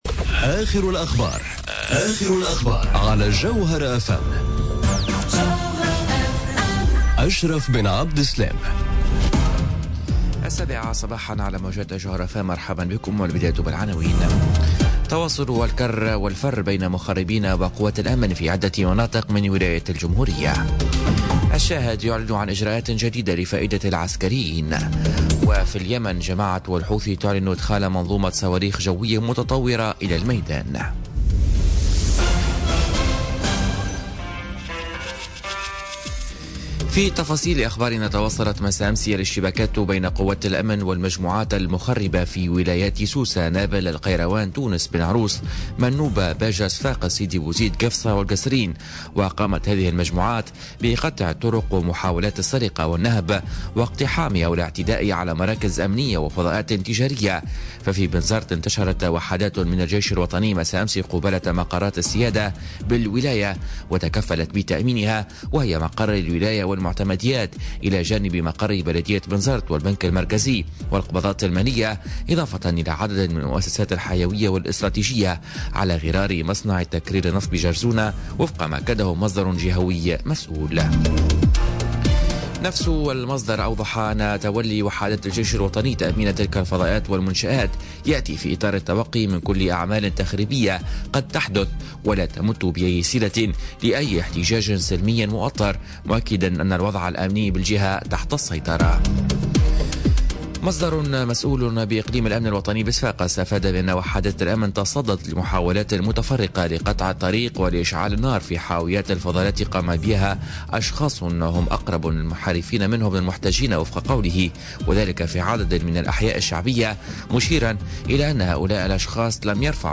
نشرة أخبار السابعة صباحا ليوم الإربعاء 10 جانفي 2017